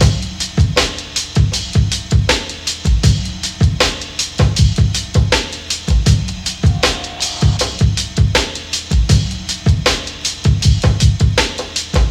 • 79 Bpm 90's Hip-Hop Drum Loop Sample G# Key.wav
Free drum loop - kick tuned to the G# note. Loudest frequency: 1686Hz
79-bpm-90s-hip-hop-drum-loop-sample-g-sharp-key-Aof.wav